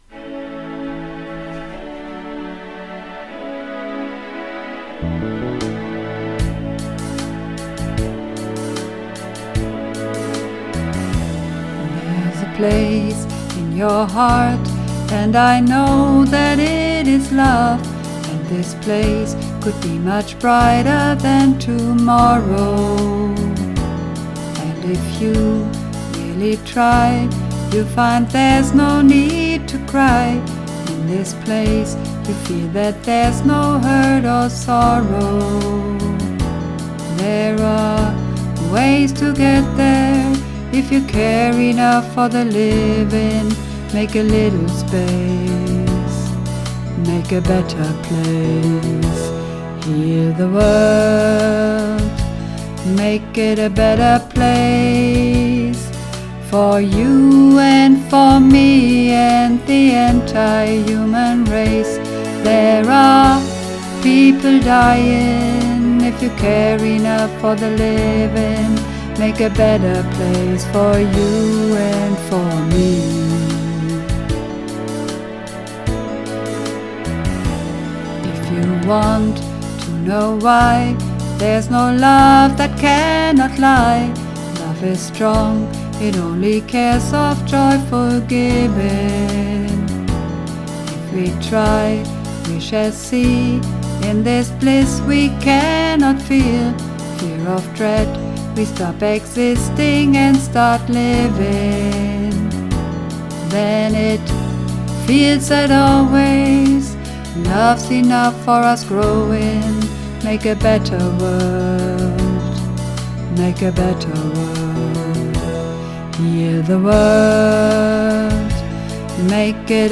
Übungsaufnahmen
Heal_The_World__2_Bass.mp3